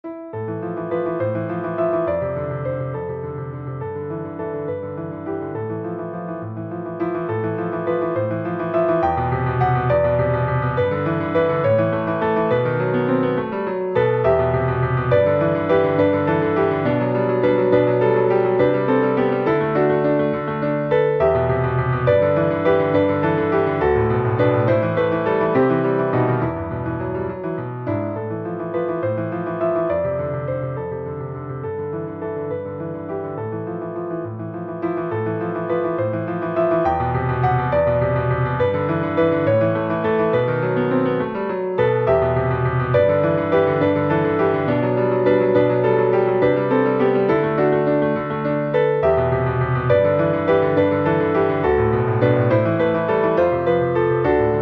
20 kleine Klavierstücke für Jugendliche & Erwachsene.